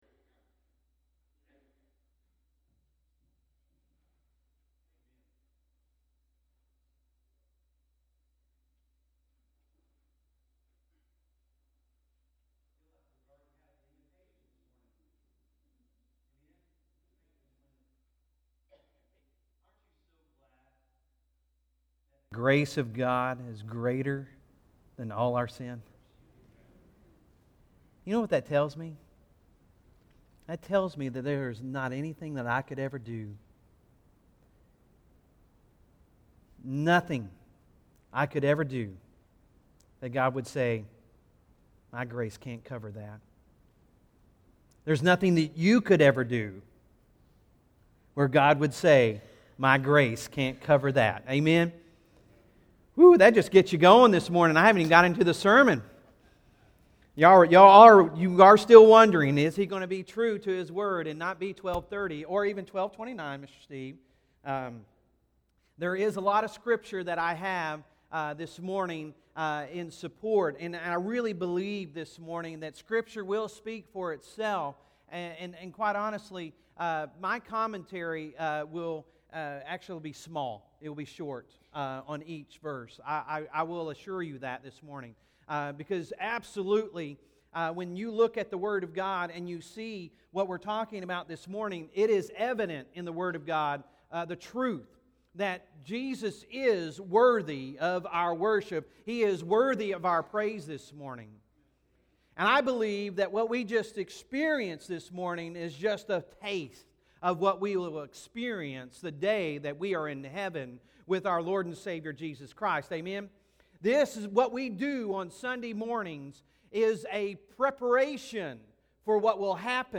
Sunday Sermon November 3, 2019